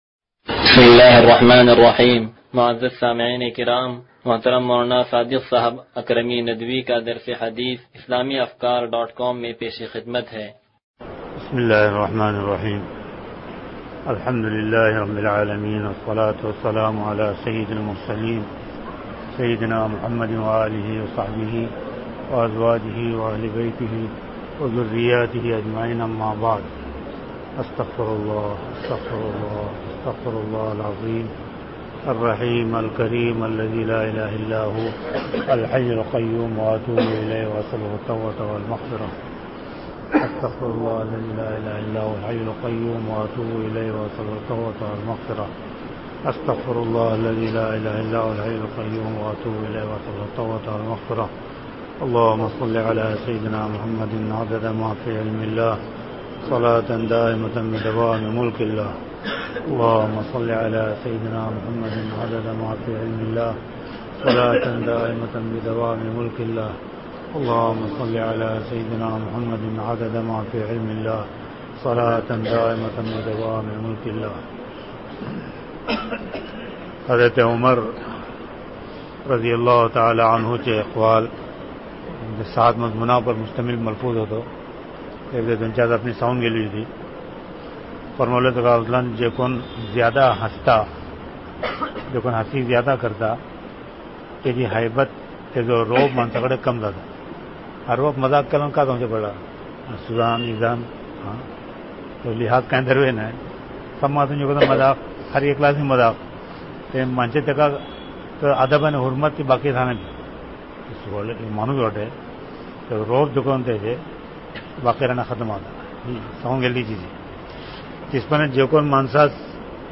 درس حدیث نمبر 0186